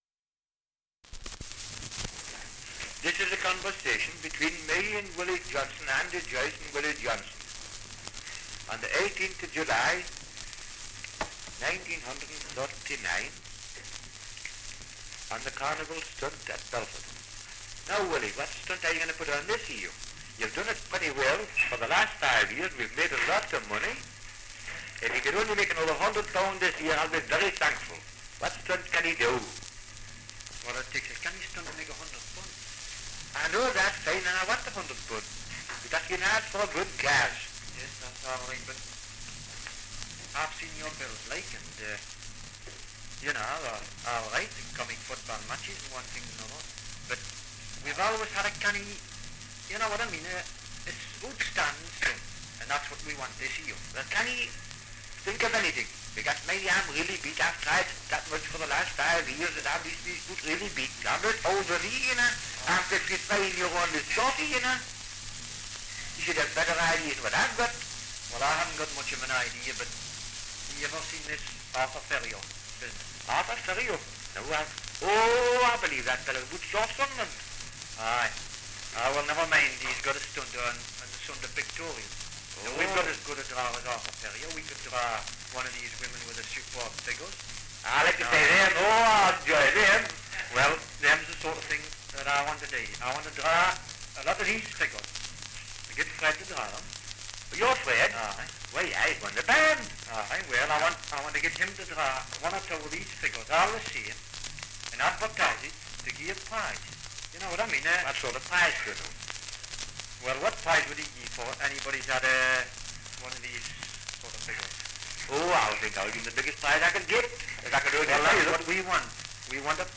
2 - Dialect recording in Belford, Northumberland
78 r.p.m., cellulose nitrate on aluminium